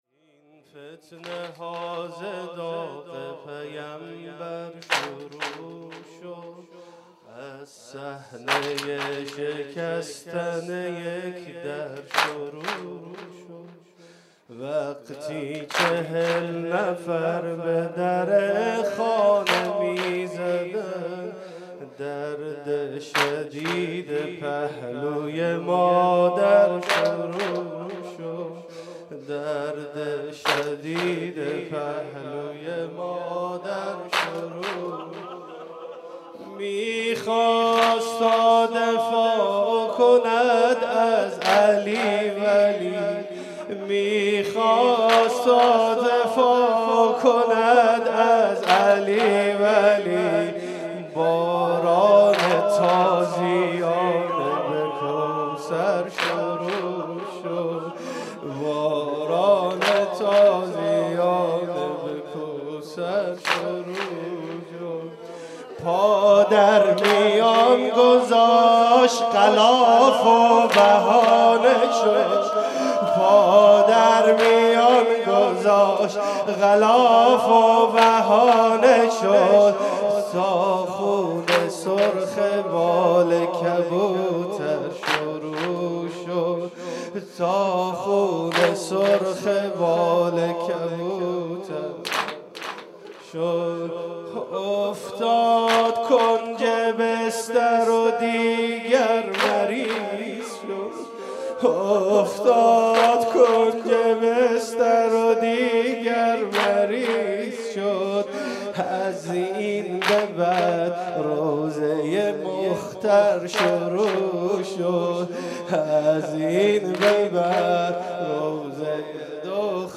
شب دوم فاطميه 95 - هيئت شبان القاسم - وای مادرم